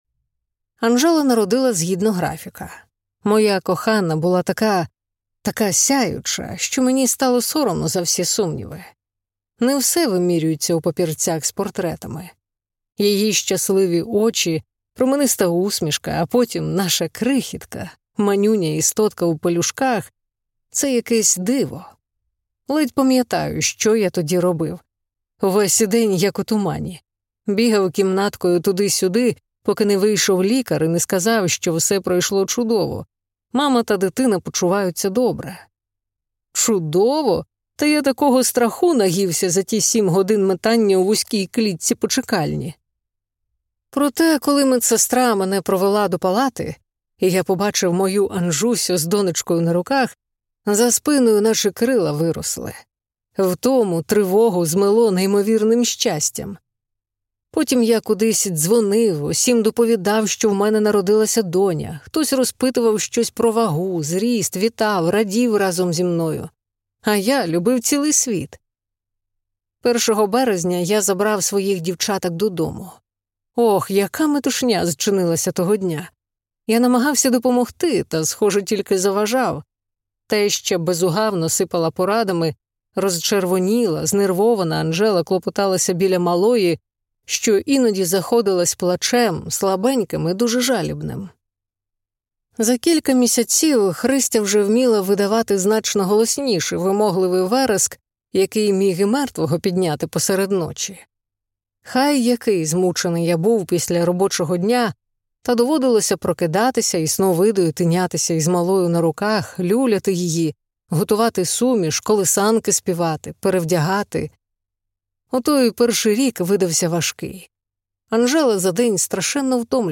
Слухайте аудіокнигу «Нова людина» Наталки Ліщинської. Жорстка соціальна фантастика про біотехнологічний апартеїд, смертельний вірус та виживання у світі без емпатії.